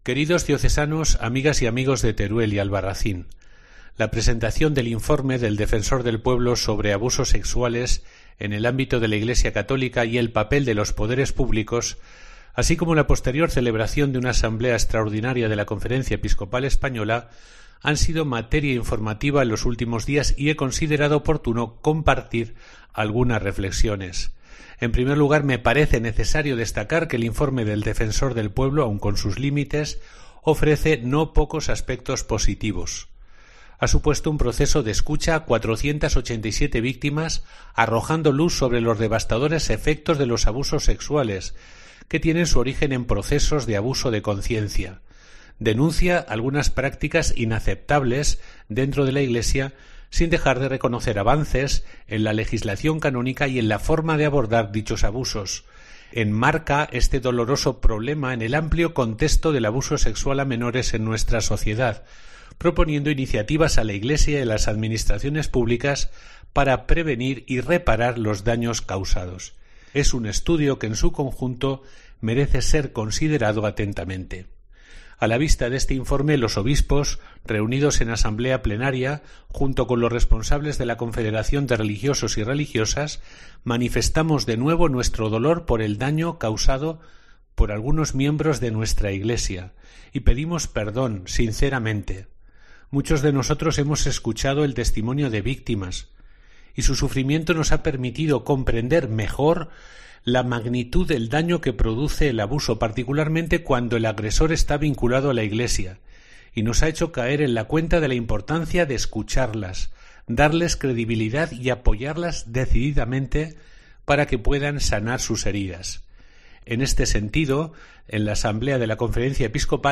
Mensaje del obispo de Teruel y Albarracín, monseñor Jose Antonio Satué, sobre los abusos sexuales.